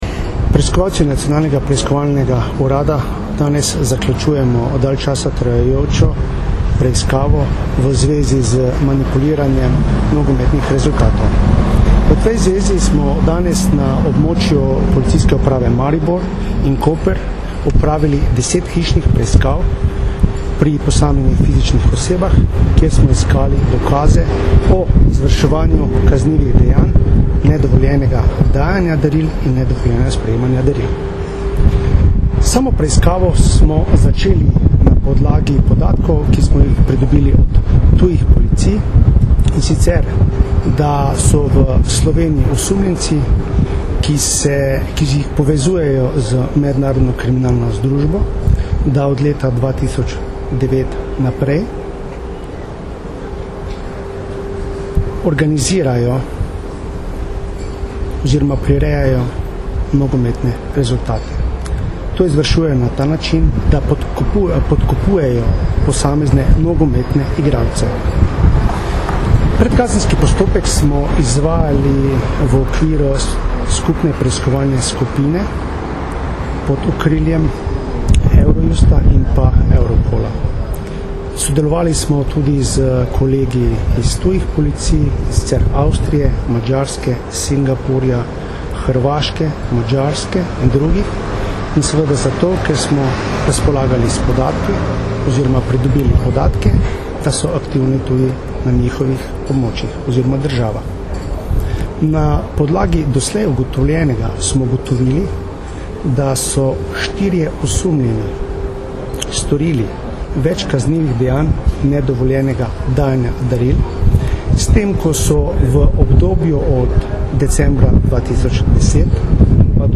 Zvočni posnetek izjave Darka Majheniča, direktorja Nacionalnega preiskovalnega urada (mp3)